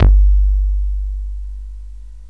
slapp cx5.wav